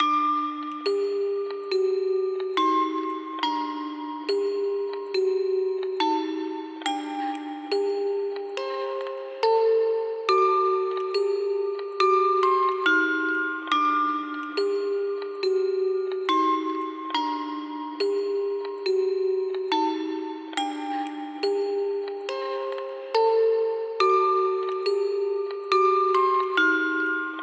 描述：使用DSK音乐盒VST的旋律
Tag: 140 bpm Trap Loops Bells Loops 4.61 MB wav Key : C